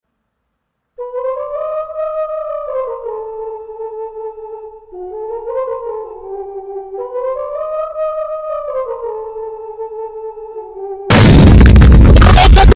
POV: Your Blowing Your Nose Sound Effects Free Download